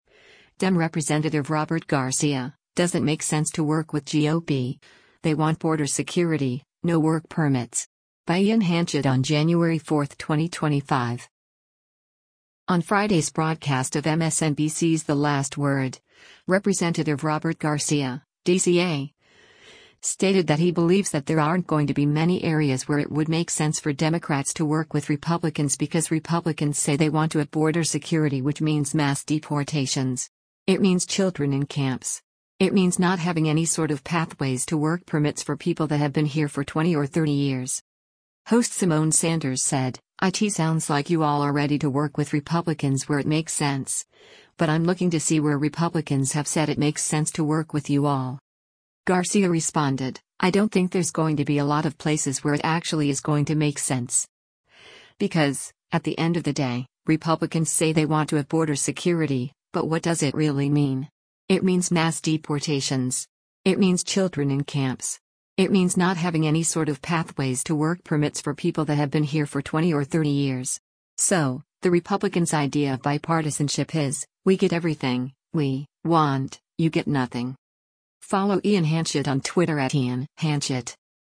On Friday’s broadcast of MSNBC’s “The Last Word,” Rep. Robert Garcia (D-CA) stated that he believes that there aren’t going to be many areas where it would make sense for Democrats to work with Republicans because “Republicans say they want to have border security” which “means mass deportations. It means children in camps. It means not having any sort of pathways to work permits for people that have been here for 20 or 30 years.”